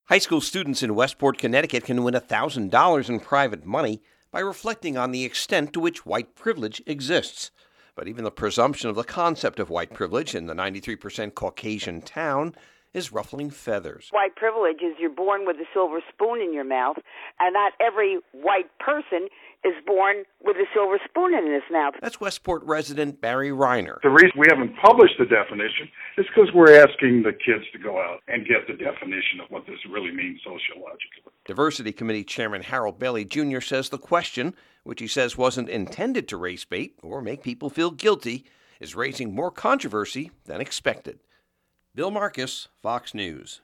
REPORTS: